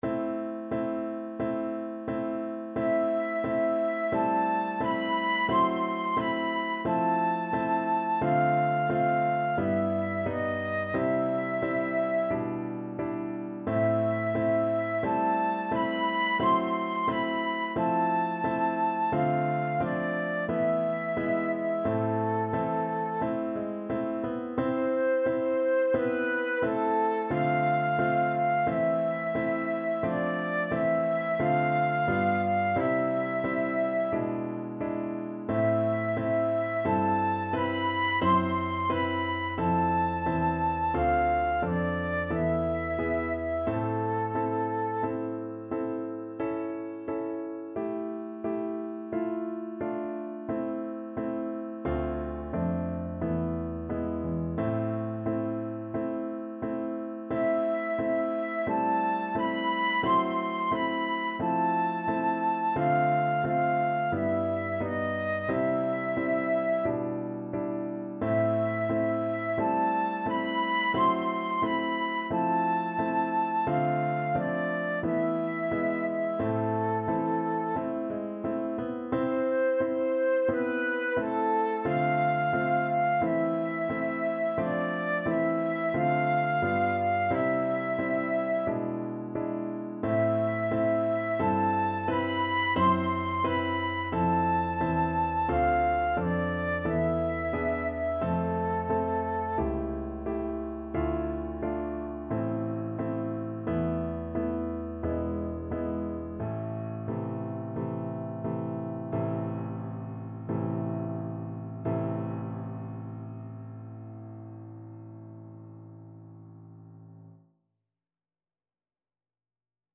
Free Sheet music for Flute
Traditional Music of unknown author.
A minor (Sounding Pitch) (View more A minor Music for Flute )
Andante =c.88
Classical (View more Classical Flute Music)